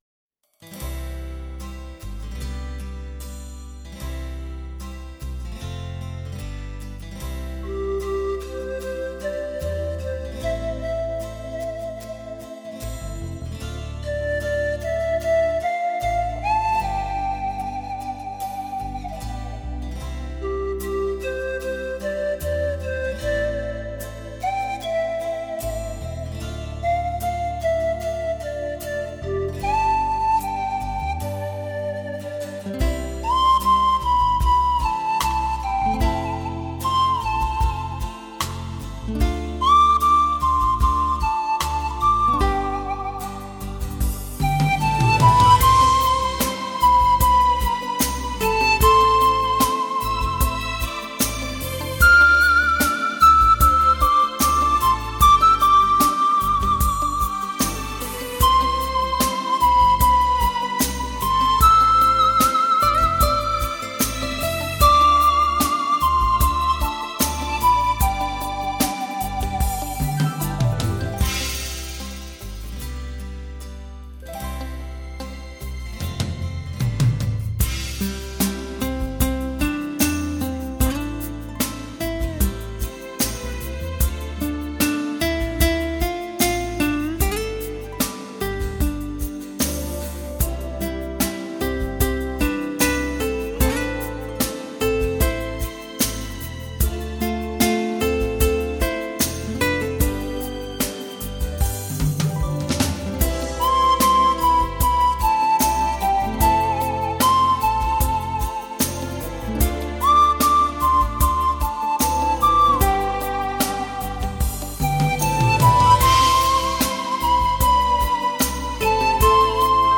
以如梦似幻的清新自然音乐
它的音乐以宽广的音场，逼真的音效，通透的音质而风靡全国